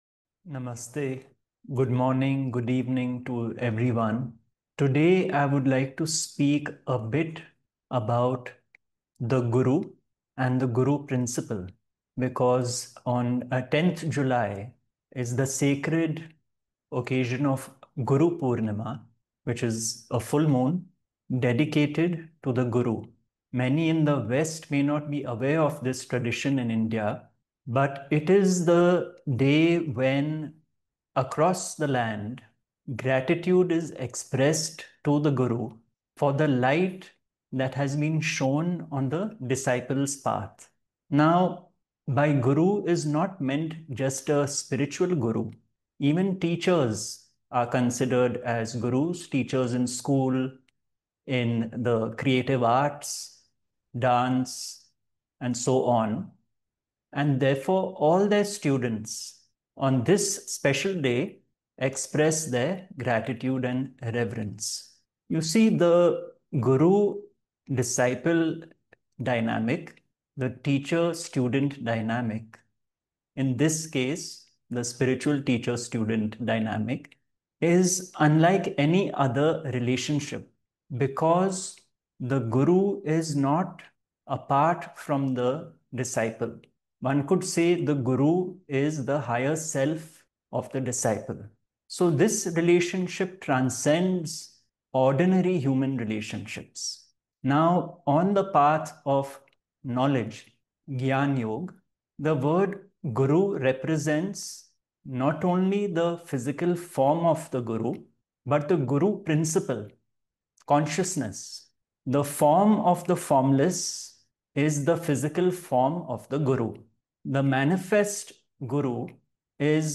From-Darkness-to-Equanimity_-The-Gurus-Gift-of-Awareness-Zoom-Talk.mp3